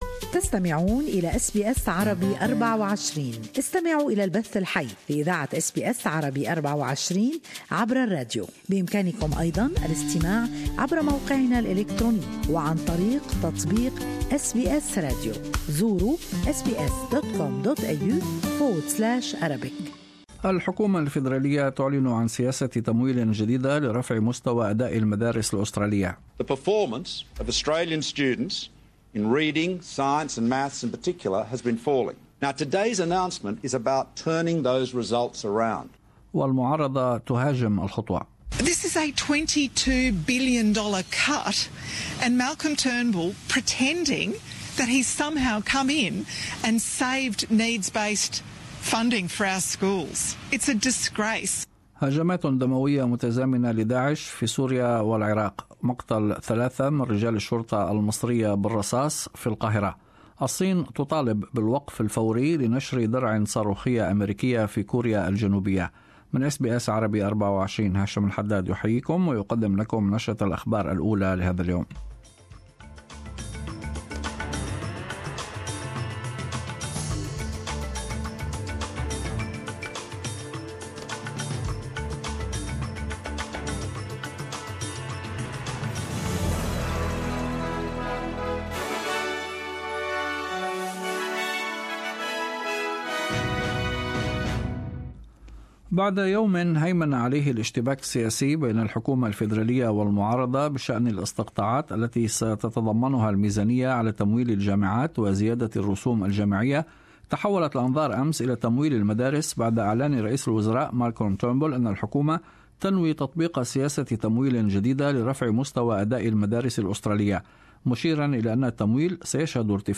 In this bulletin ... * Donald Trump and Vladimir Putin have their first talks since last month's U-S air strikes on Syria * Theresa May talks up her toughness And * A man dies in a Melbourne shooting.